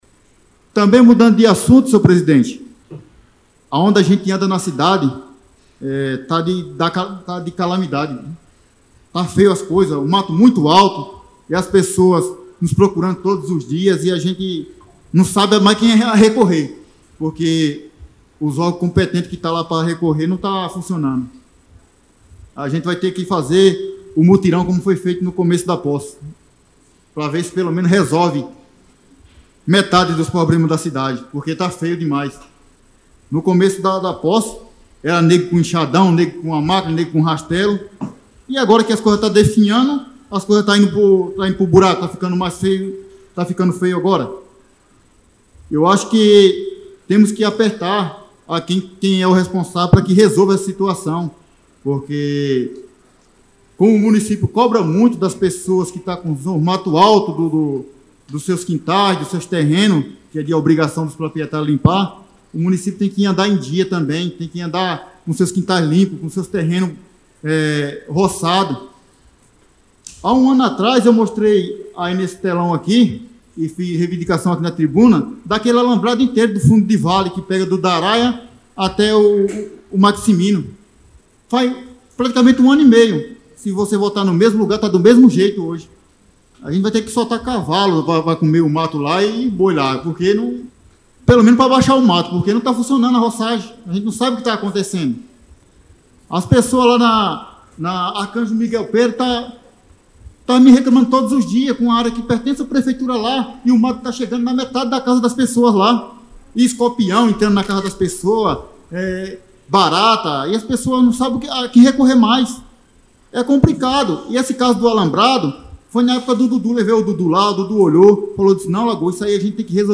LAGOA-fala-na-camara-dia-19.mp3